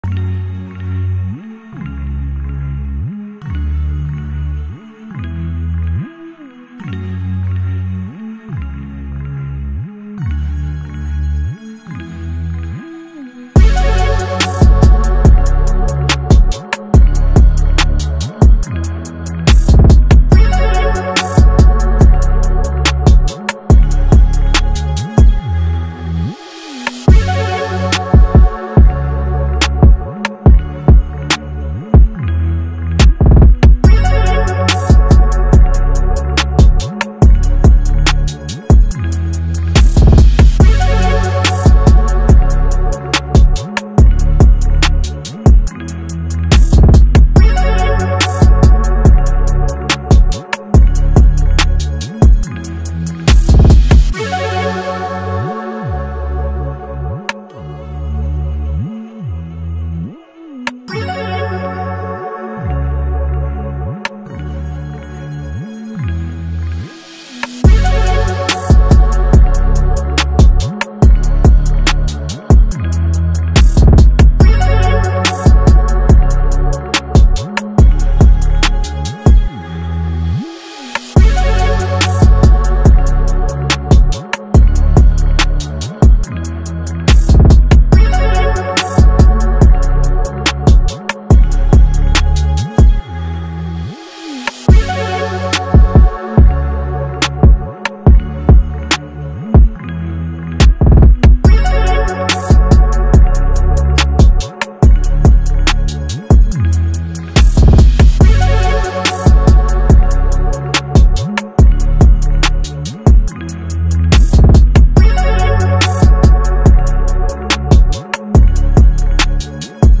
Type Beat
Hard Instrumental 2025